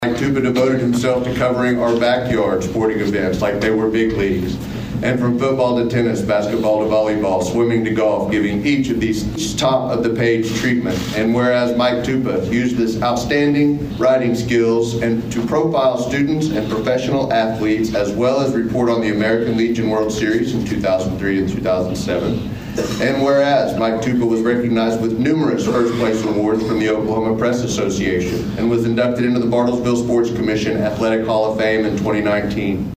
State Representative Judd Strom read a citation of appreciation